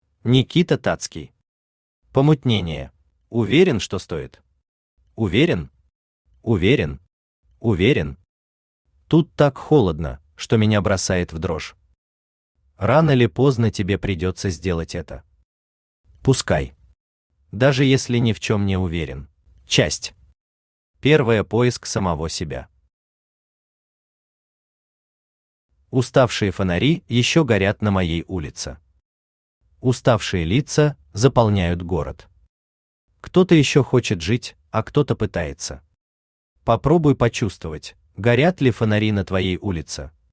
Аудиокнига Помутнение | Библиотека аудиокниг
Aудиокнига Помутнение Автор Никита Тацкий Читает аудиокнигу Авточтец ЛитРес.